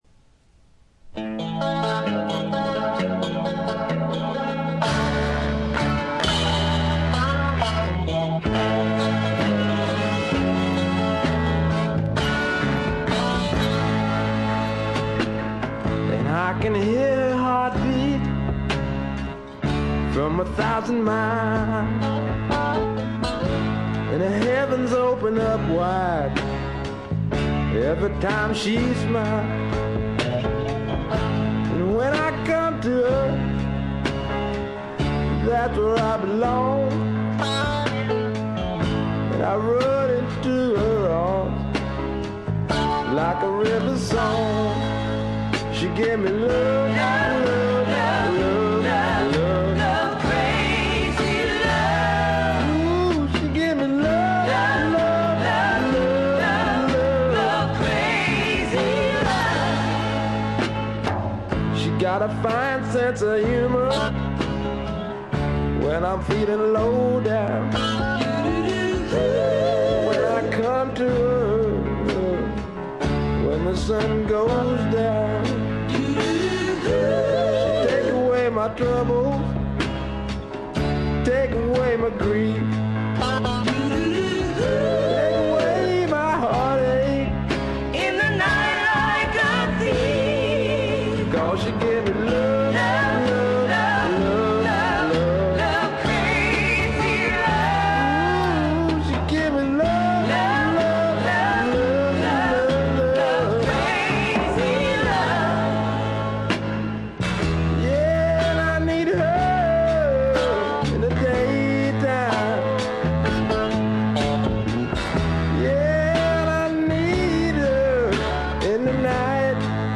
軽微なチリプチ少し。
まさしくスワンプロックの真骨頂。
試聴曲は現品からの取り込み音源です。